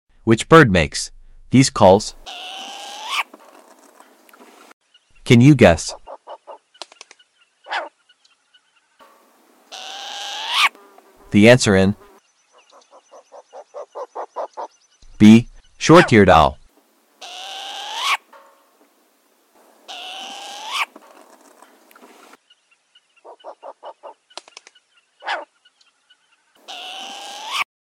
Which bird makes these calls..?..Can sound effects free download
bird call bird sounds birds